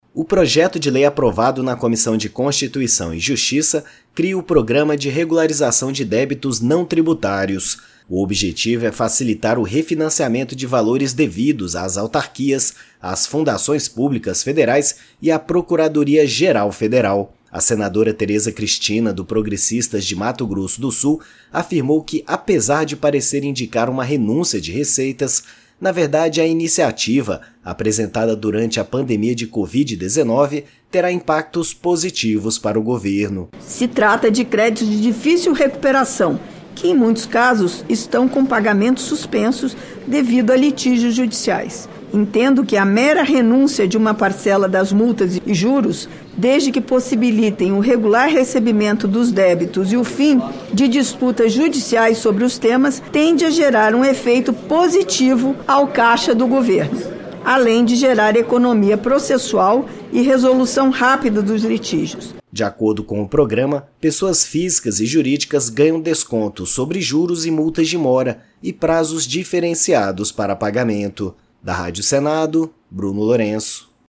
A senadora Teresa Cristina (PP-MS) diz que, apesar de indicar renúncia de receitas, na verdade, a iniciativa terá impactos positivos para o Governo pois trata de créditos de difícil recuperação.